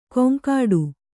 ♪ koŋkāḍu